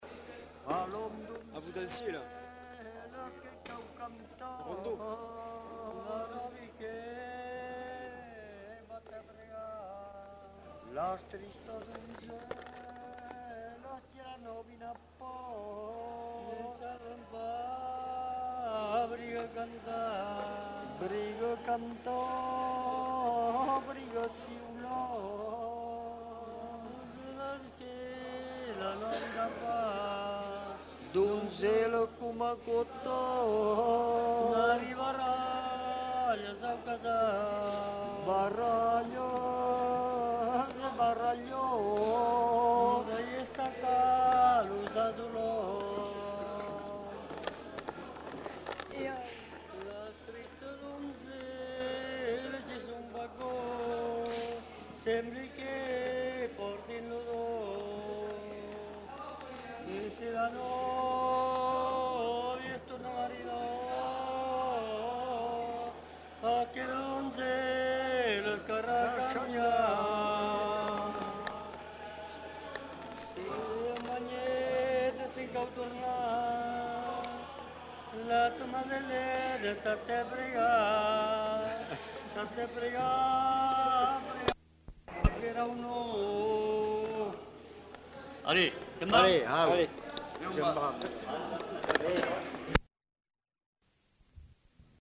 Genre : chant
Effectif : 2
Type de voix : voix d'homme
Production du son : chanté
Contextualisation de l'item : Se chantait le jour de la noce. Les deux hommes chantent en alternance.
Ecouter-voir : archives sonores en ligne